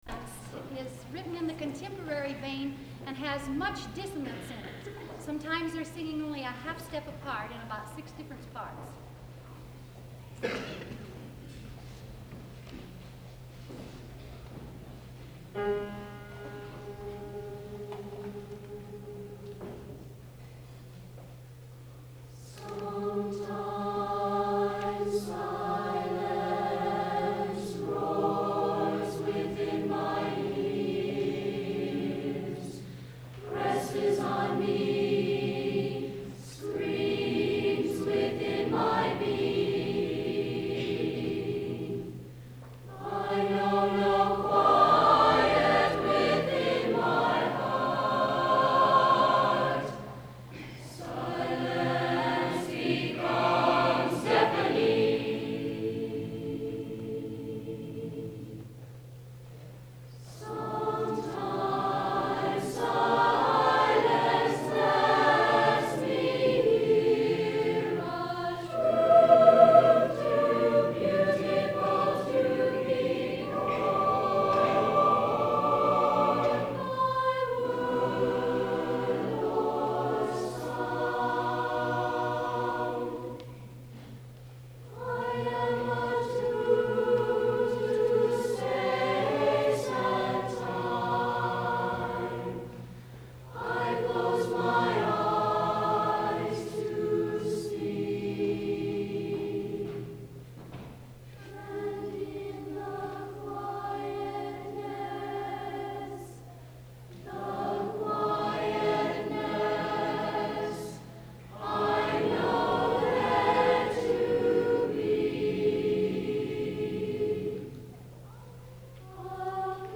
Spring Concert
Clay High Gym